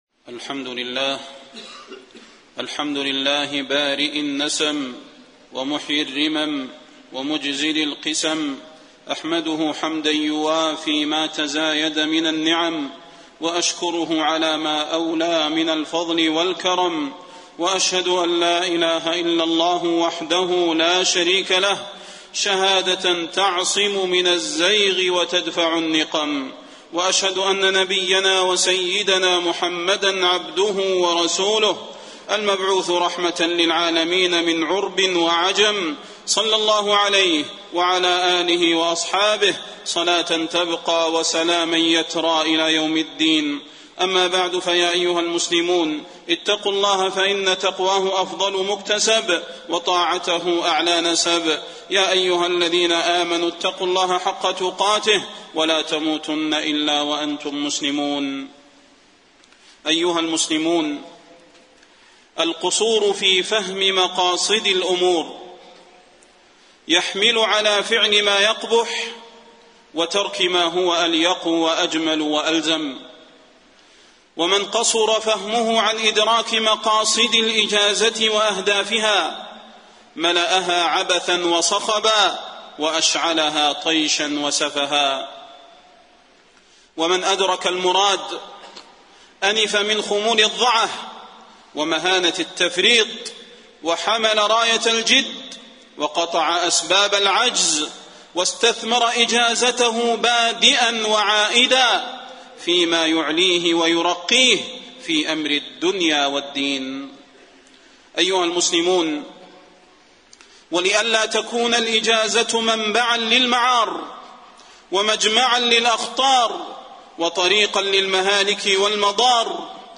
خطبة الجمعة 7-8-1432 هـ | موقع المسلم
خطب الحرم المكي